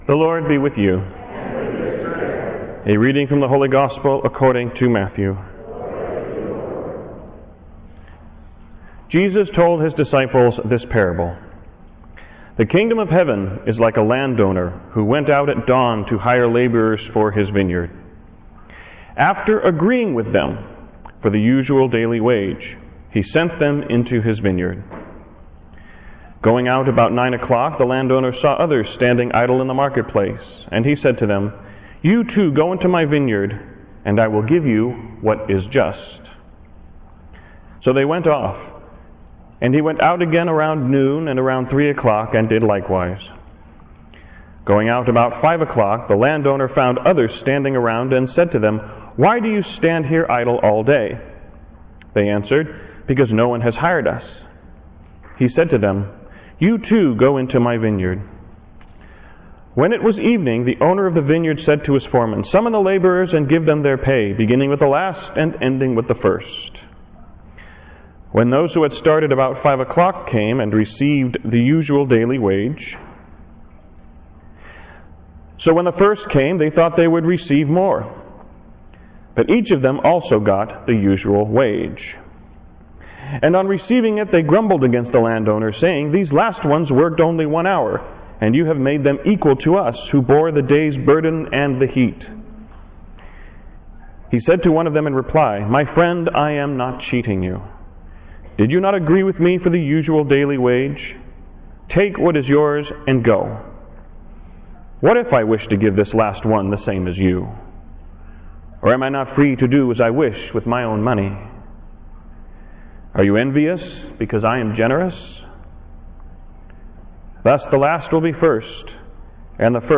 Homily-25thSundayANoTurningBack-LaborersintheVineyard.wav